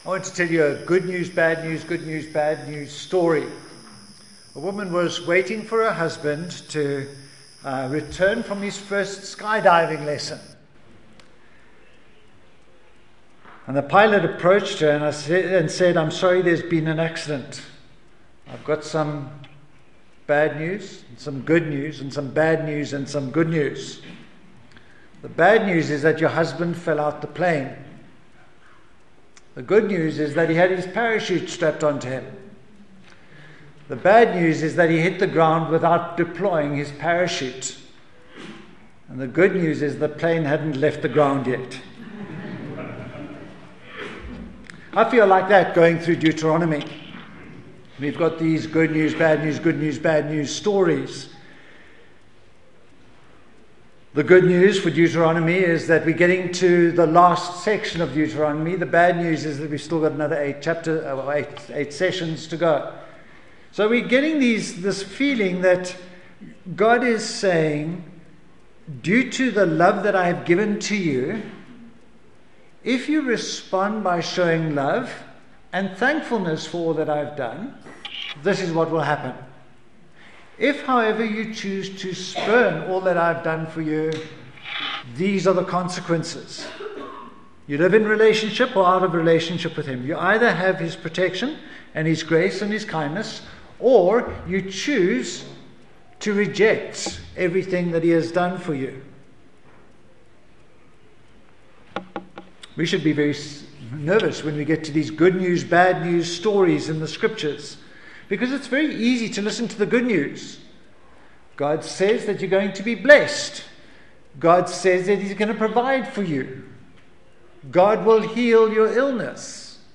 Deuteronomy 28 Service Type: Sunday Morning Shut up and listen.